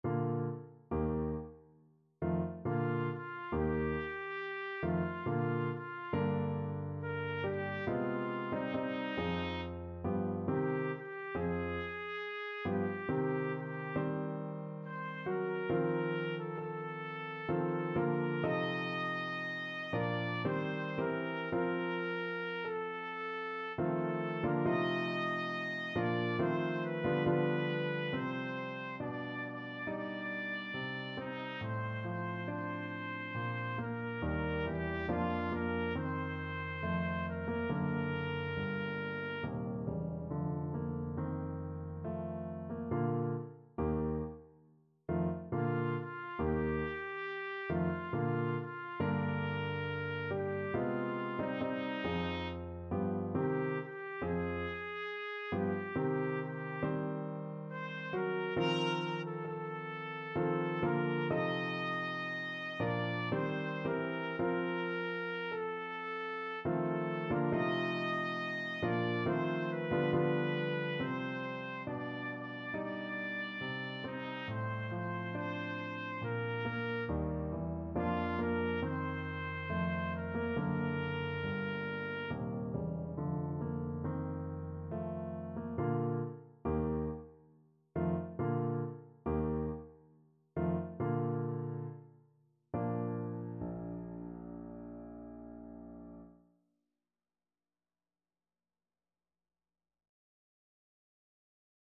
Classical Schumann, Robert Seit ich ihn gesehen (No. 1 from Frauenliebe und Leben) Trumpet version
Bb major (Sounding Pitch) C major (Trumpet in Bb) (View more Bb major Music for Trumpet )
3/4 (View more 3/4 Music)
Larghetto =69
Classical (View more Classical Trumpet Music)